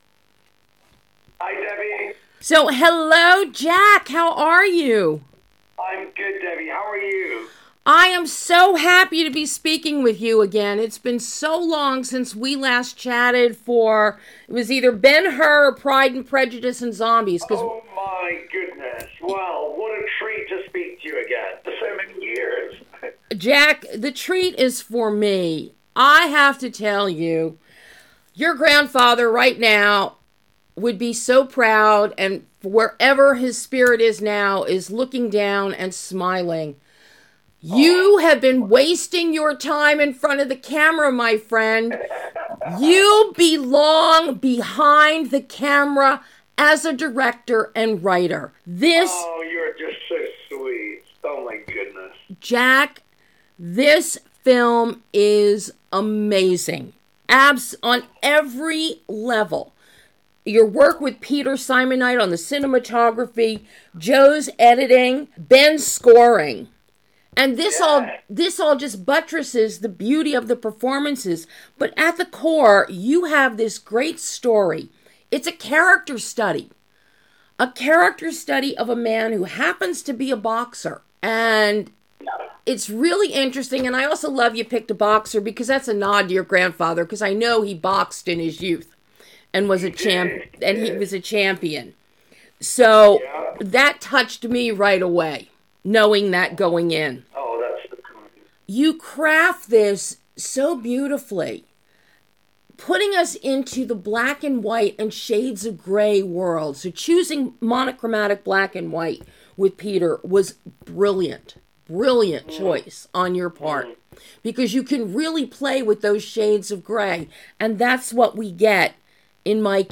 JACK HUSTON delivers a directorial knockout with DAY OF THE FIGHT - Exclusive Interview
A joyful and insightful exclusive interview with writer/director JACK HUSTON discussing DAY OF THE FIGHT, his narrative feature directorial debut.
Jack-Huston-edited-DAY-OF-THE-FIGHT-1.mp3